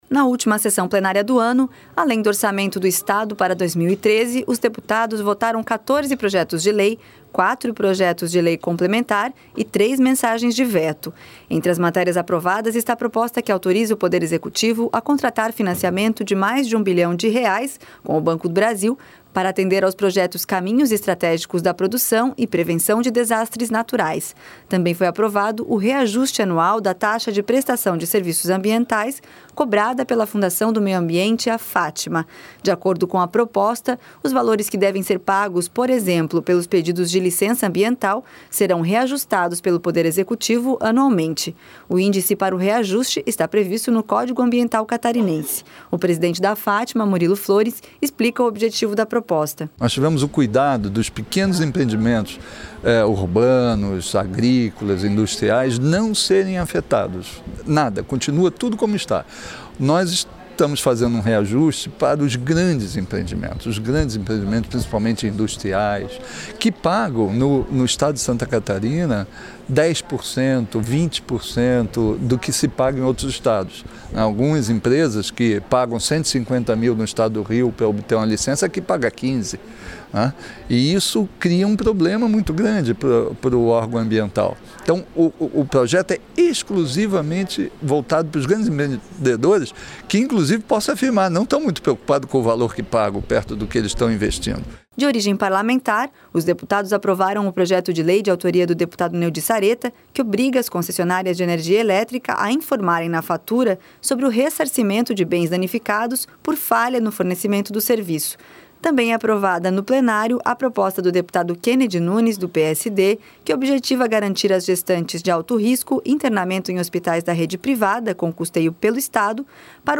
O presidente da Fatma, Murilo Flores,  explica o objetivo da proposta.